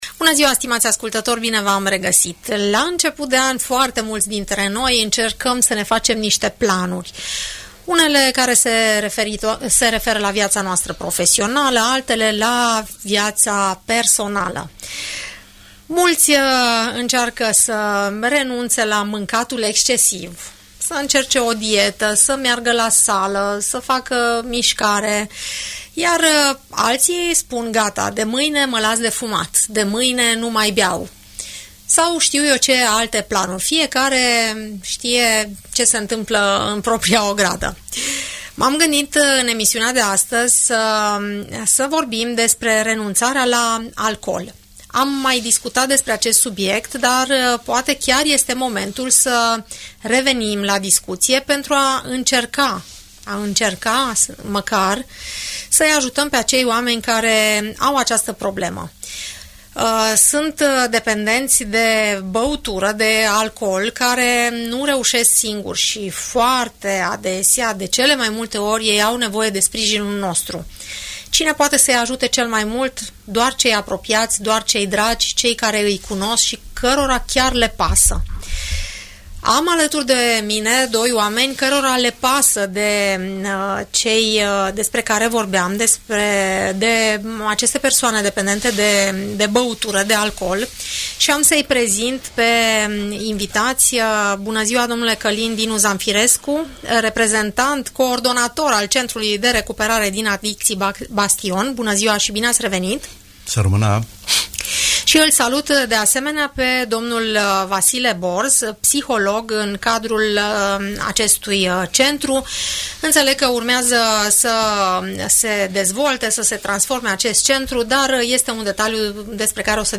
Aceasta despre concluzia care rezultă în urma discuției de la Radio Tg. Mureș